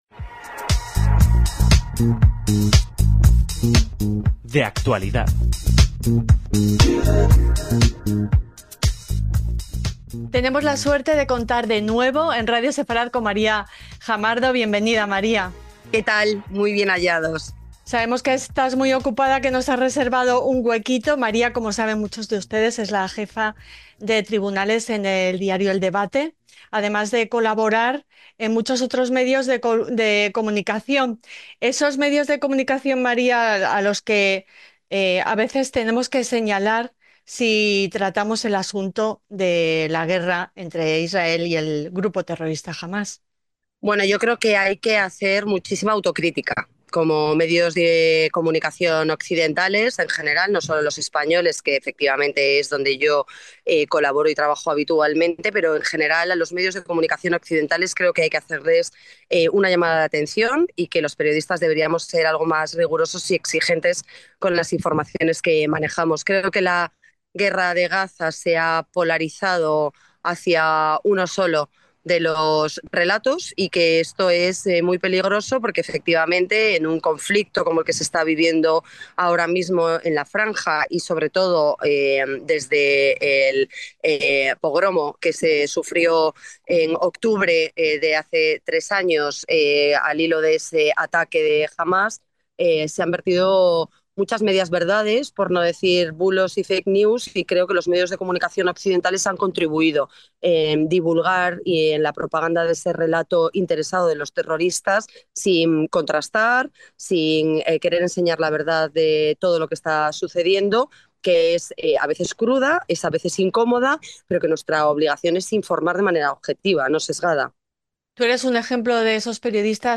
En esta entrevista